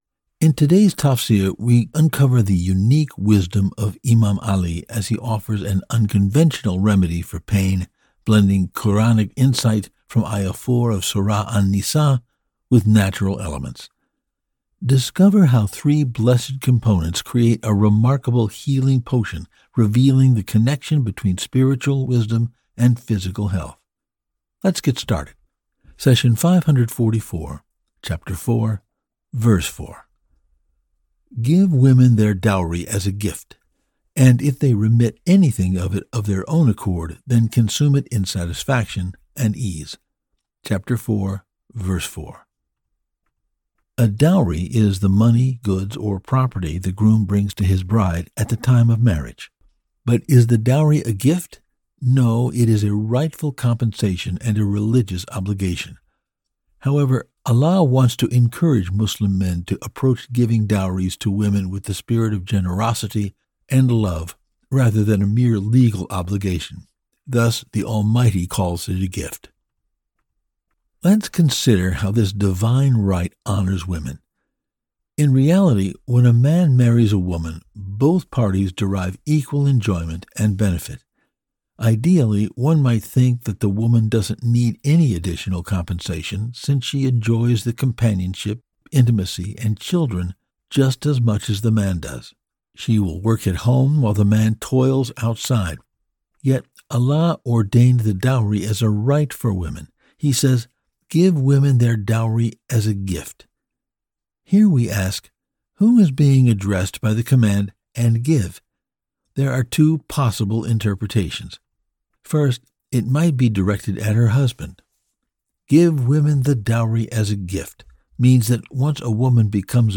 The Nobel Quran Explained in Clear English; professionally narrated and delivered to you weekly!